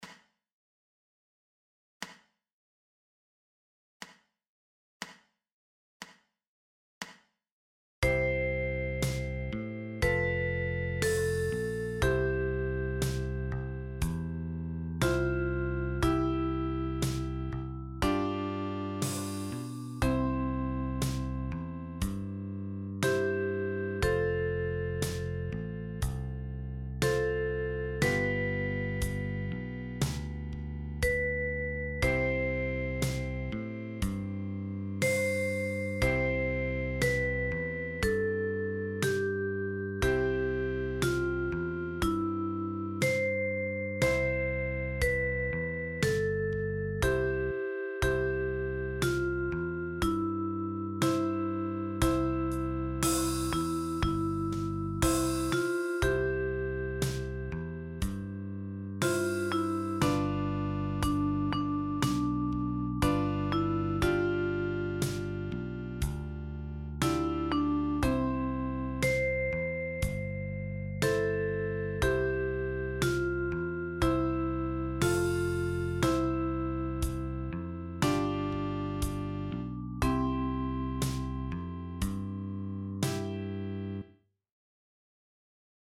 Sound samples – melody & band and band alone: